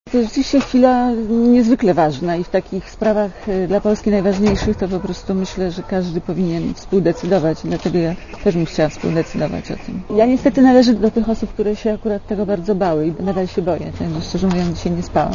W całym procesie integracji z Unią to jest dzień, który przeżywam najbardziej - powiedziała reporterowi Radia Zet Pani minister.
Mówi Danuta Huebner (60 KB)